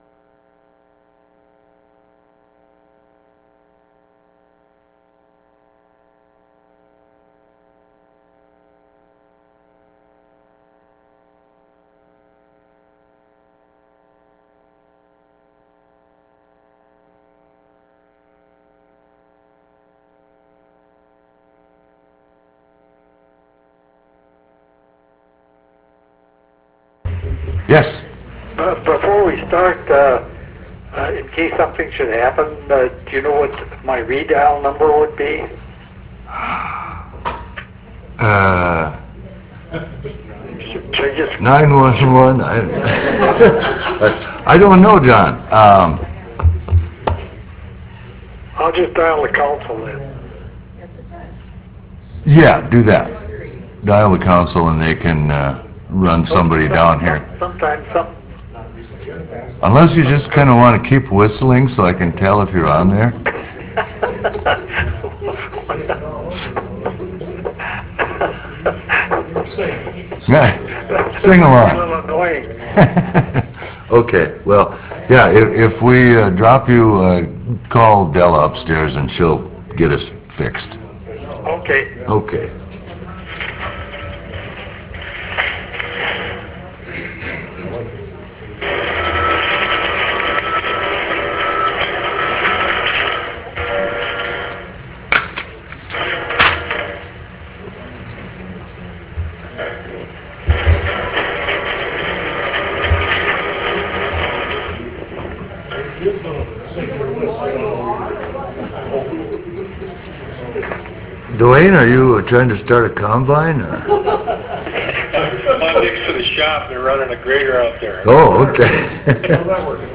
Roughrider Room State Capitol Bismarck, ND United States
Meeting Audio